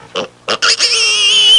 Piglet Sound Effect
Download a high-quality piglet sound effect.
piglet.mp3